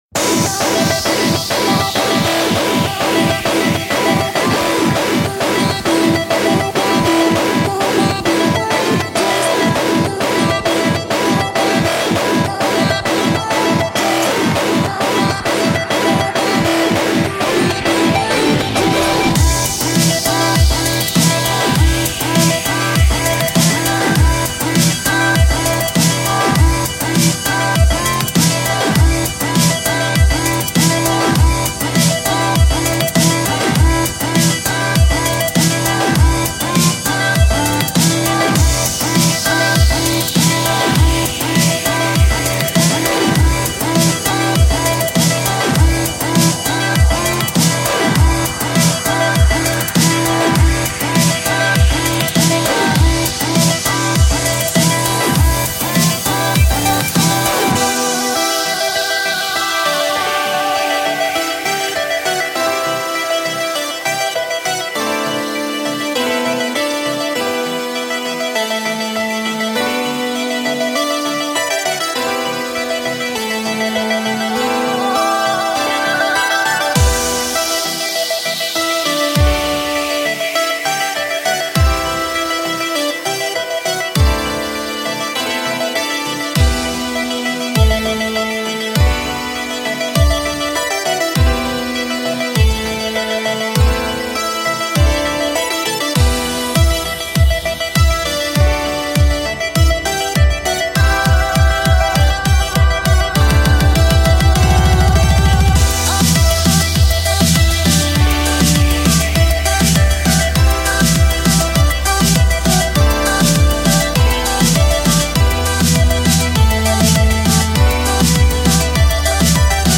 This one's Dubstep-ish
Song Key: Emin BPM: 100 Genra: Electro-cor